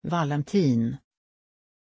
Pronunciation of Vallentin
pronunciation-vallentin-sv.mp3